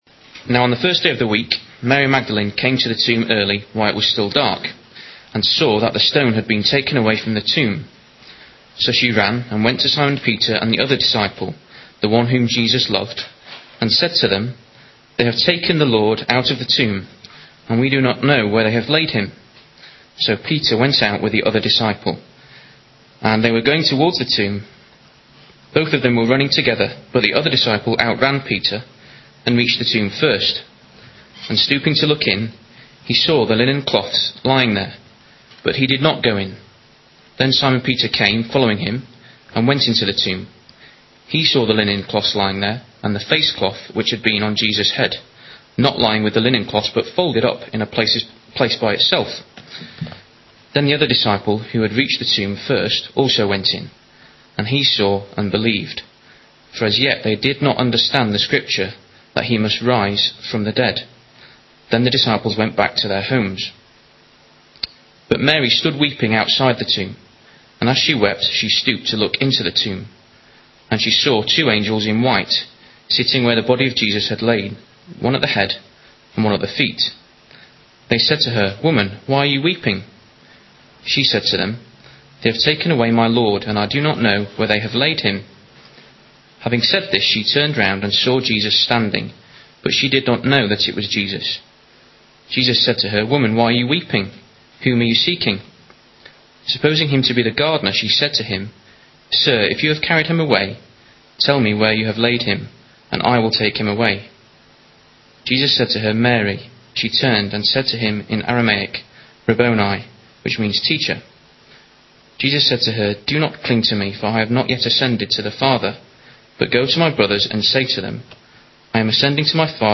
Bible Talks | Christ Church Central | Sheffield